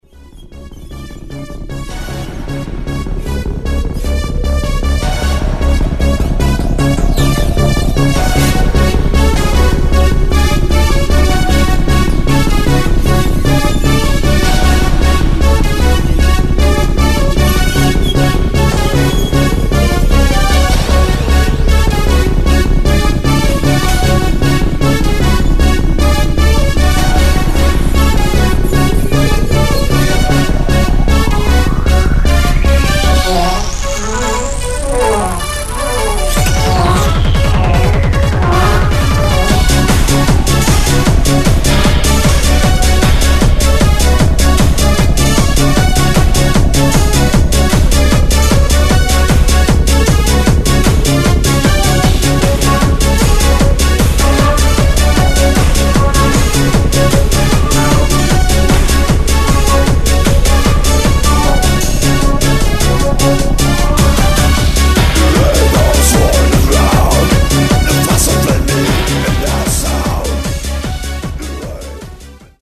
Electro-industrial/EBM durvulás Nagoya-ból.